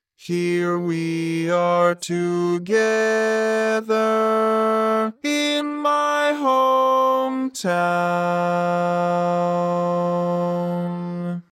Key written in: F Major
Type: Female Barbershop (incl. SAI, HI, etc)